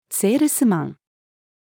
salesman-female.mp3